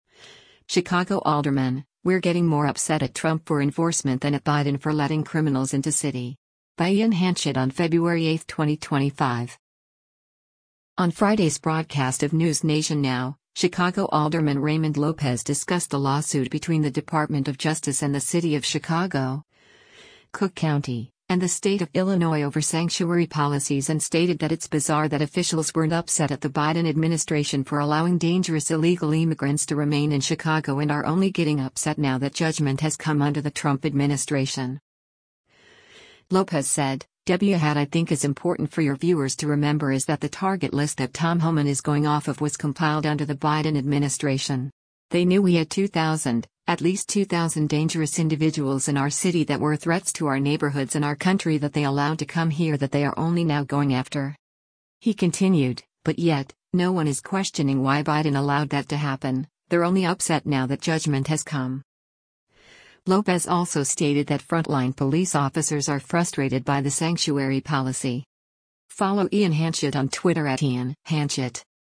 On Friday’s broadcast of “NewsNation Now,” Chicago Alderman Raymond Lopez discussed the lawsuit between the Department of Justice and the city of Chicago, Cook County, and the state of Illinois over sanctuary policies and stated that it’s bizarre that officials weren’t upset at the Biden administration for allowing dangerous illegal immigrants to remain in Chicago and are only getting “upset now that judgment has come” under the Trump administration.